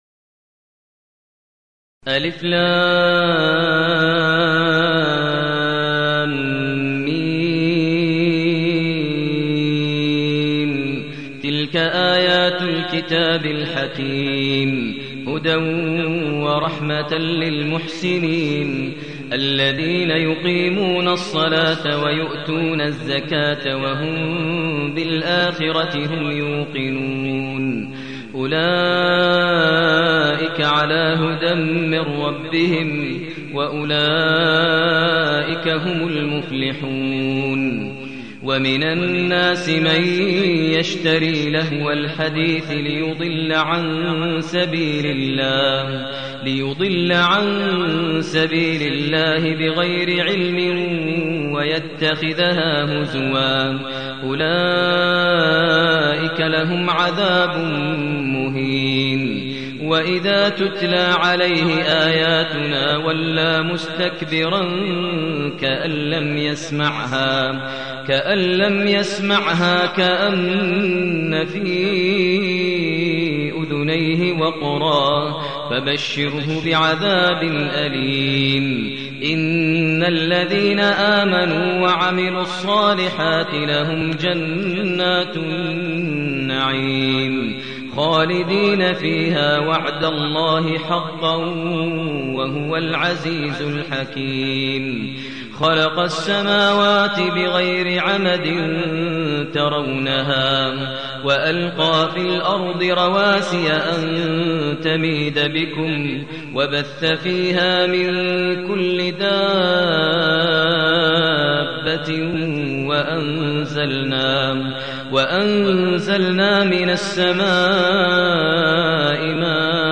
المكان: المسجد الحرام الشيخ: فضيلة الشيخ ماهر المعيقلي فضيلة الشيخ ماهر المعيقلي لقمان